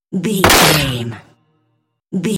Dramatic hit bloody laser
Sound Effects
heavy
intense
dark
aggressive